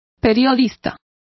Complete with pronunciation of the translation of reporter.